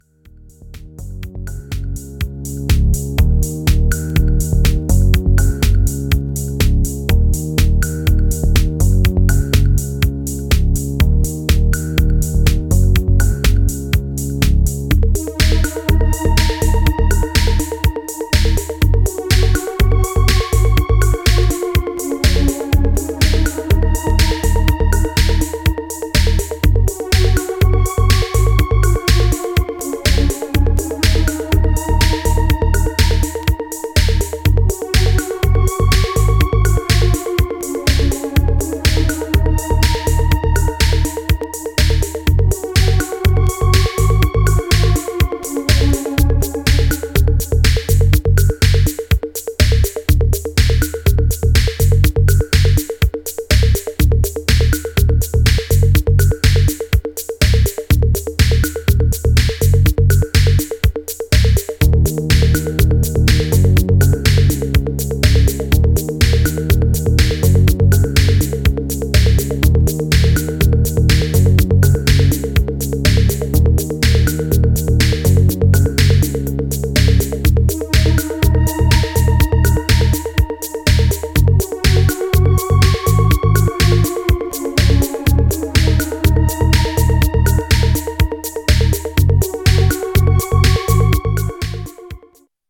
Styl: House, Breaks/Breakbeat, Minimal